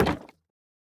Minecraft Version Minecraft Version 1.21.5 Latest Release | Latest Snapshot 1.21.5 / assets / minecraft / sounds / block / bamboo_wood_trapdoor / toggle4.ogg Compare With Compare With Latest Release | Latest Snapshot
toggle4.ogg